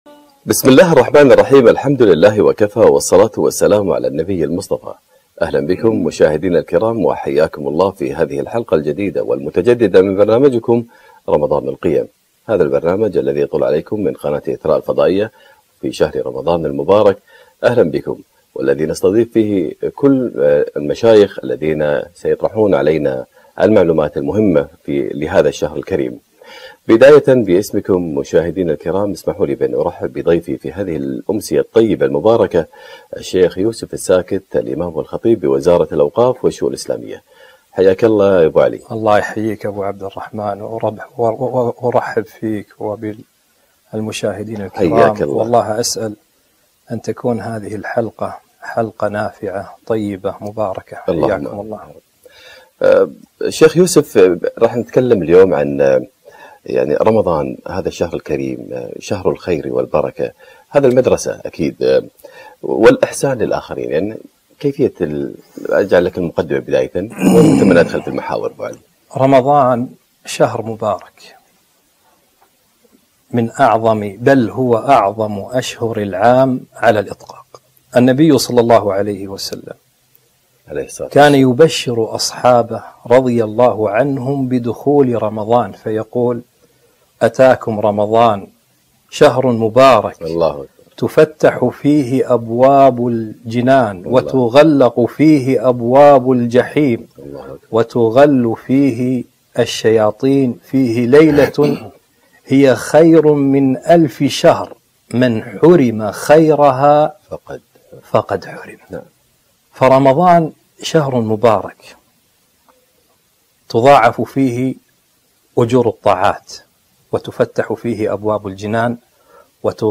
رمضان القيم - لقاء إذاعي عبر قناة إثراء 1440 هـ